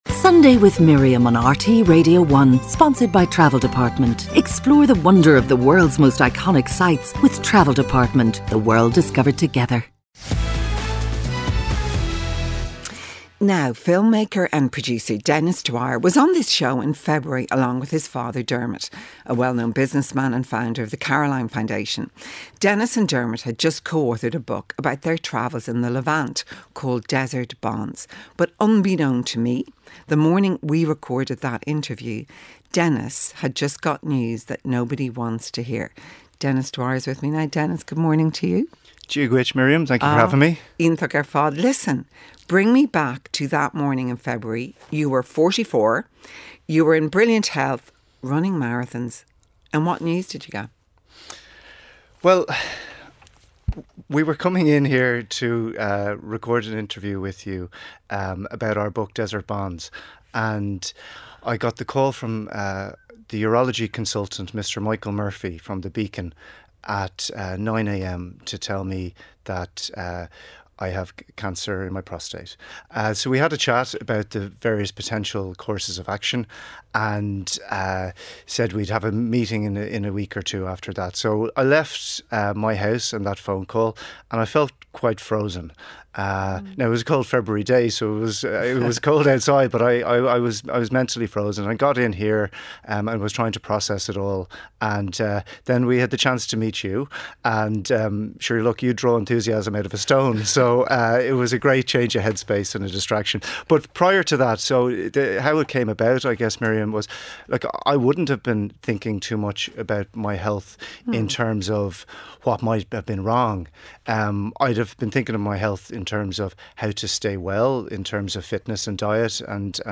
Miriam O'Callaghan presents an all-talking, all-singing, all-human-life-is-here show.